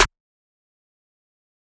Snares
New Metro Snare.wav